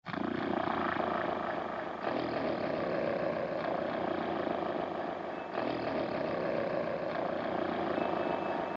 杂项声音 " 打鼾的声音
描述：柔软细腻的打鼾
Tag: 柔和 含蓄 鼾声